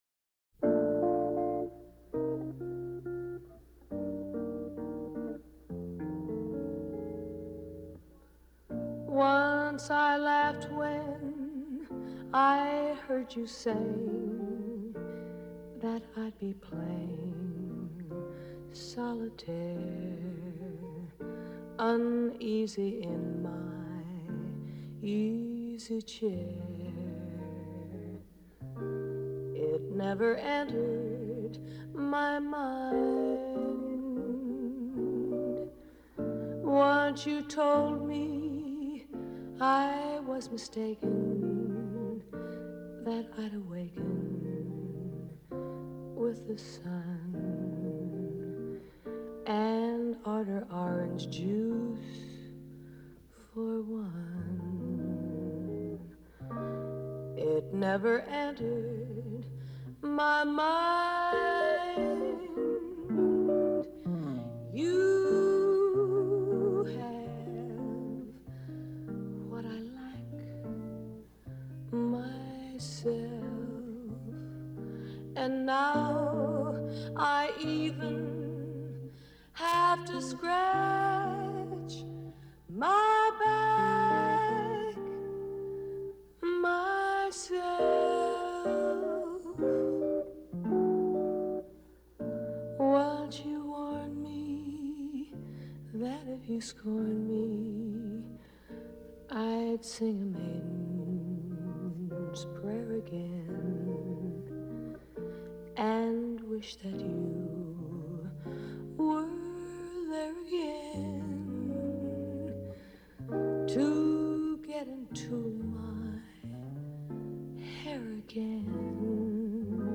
Recorded in 1955. Vocals and guitar